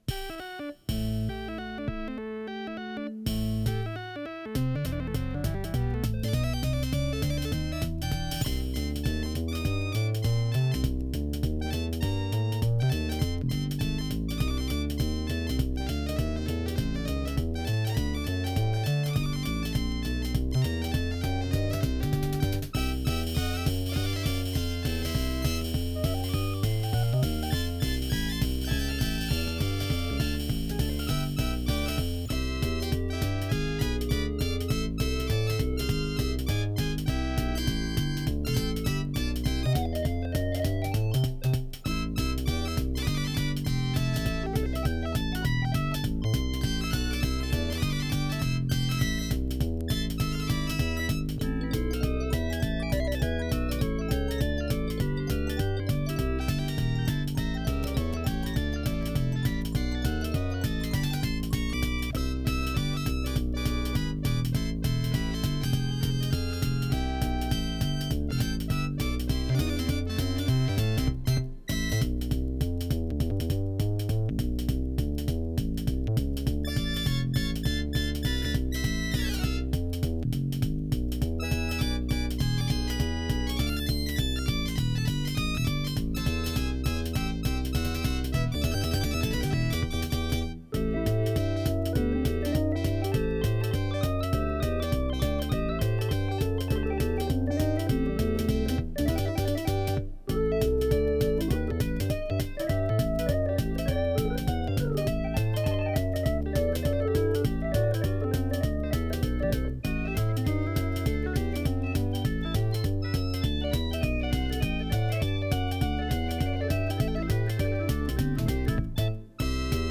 синтезаторы, компьютеры
ВариантДубль моно